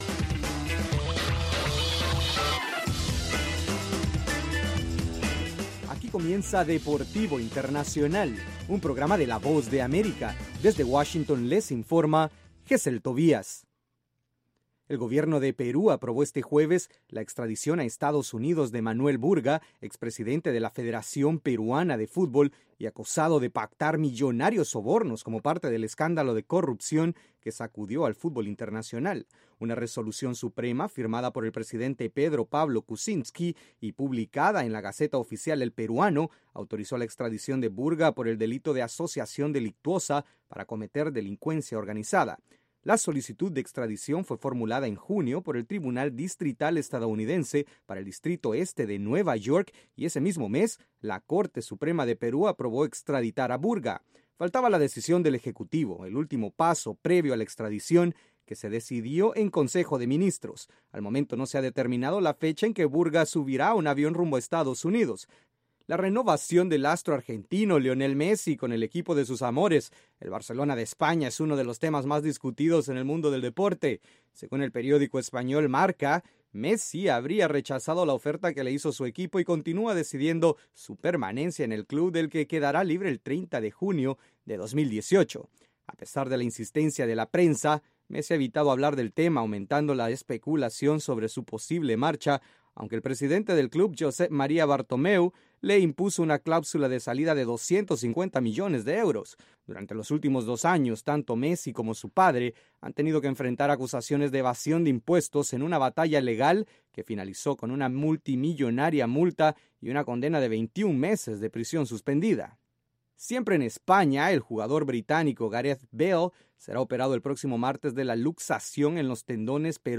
La información deportiva en cinco minutos, desde los estudios de la Voz de América.